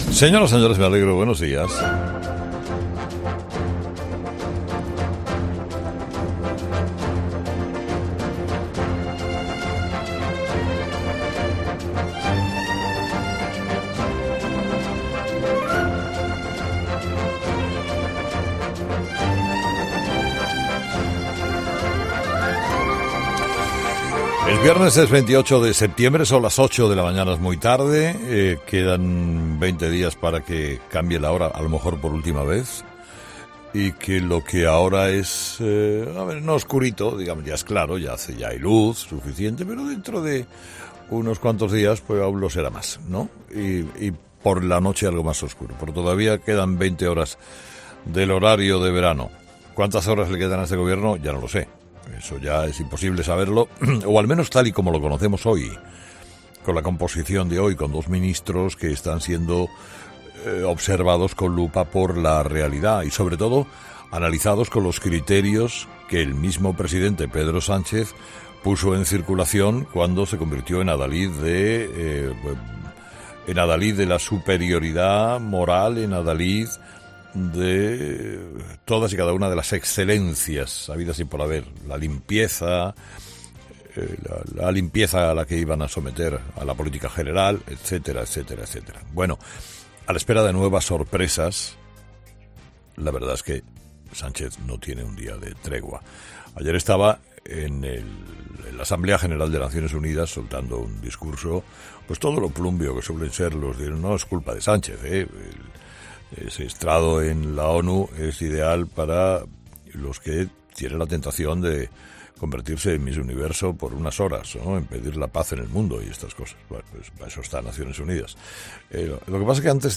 Monólogo de Herrera del viernes 28 de septiembre